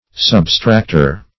substractor - definition of substractor - synonyms, pronunciation, spelling from Free Dictionary
Search Result for " substractor" : The Collaborative International Dictionary of English v.0.48: Substractor \Sub*stract"or\, n. 1. One who subtracts.